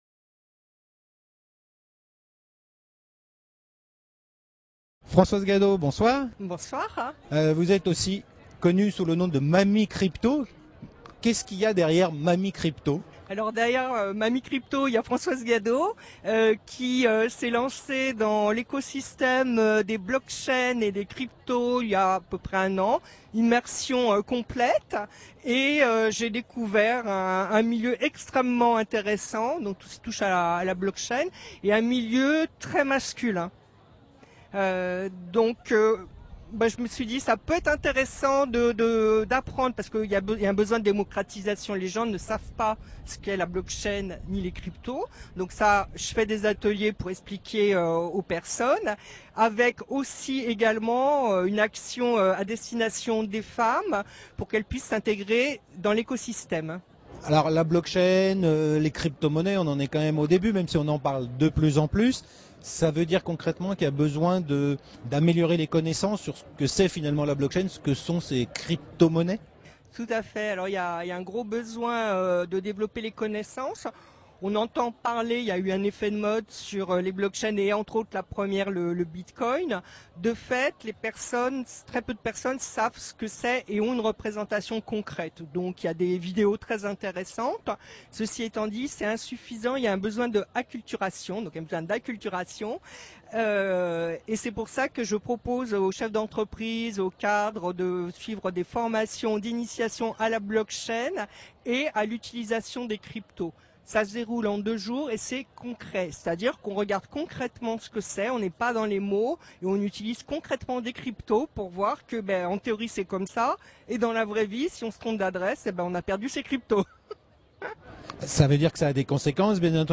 Toutes les interviews Blockchain, Crypto, réalisées lors de cet évènement sont visibles via ce lien : Vidéos Blockchain